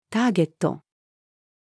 ターゲット-female.mp3